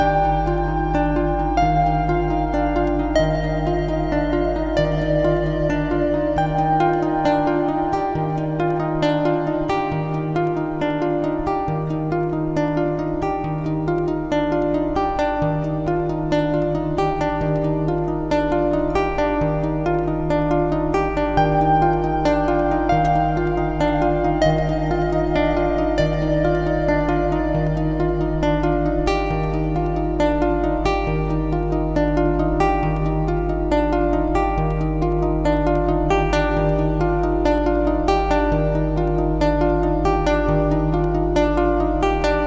Odd Times Listen to the loop: Download it here.